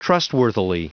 Prononciation du mot trustworthily en anglais (fichier audio)
Prononciation du mot : trustworthily